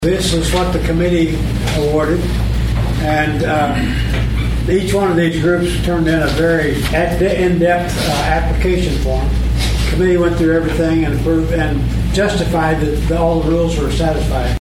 St. Francois County Presiding Commissioner, Harold Gallaher, says a total just under $330,000 was granted by the opioid settlement committee to six different organizations, all of which submitted an application for review.